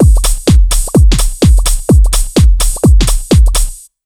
127BEAT6 1-R.wav